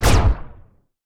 etfx_explosion_plasma.wav